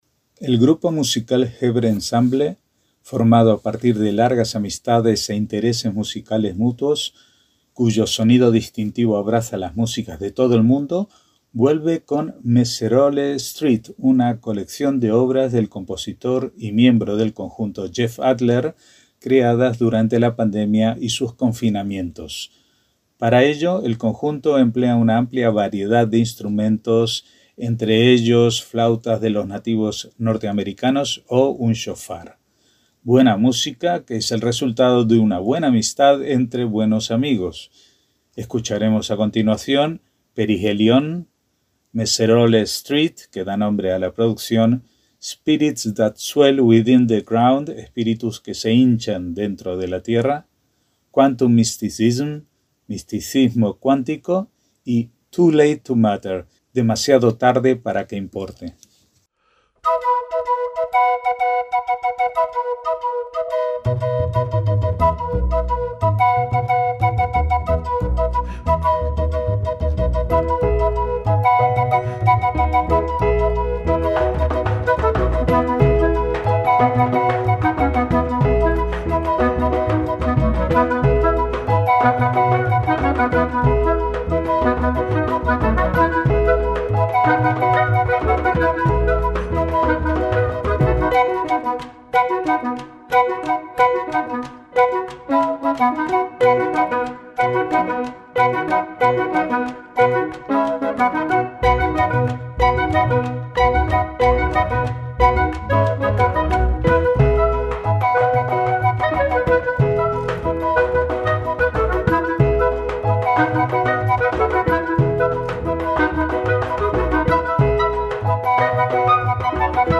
MÚSICA CLÁSICA
cuyo sonido distintivo abraza las músicas de todo el mundo